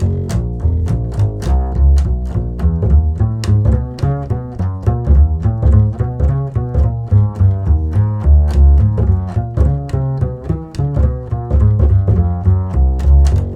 -JP WALK F#.wav